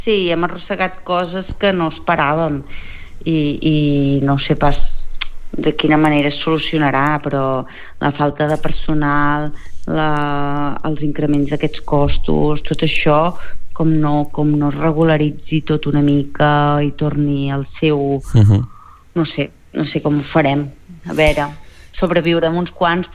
Entrevistes SupermatíNotícies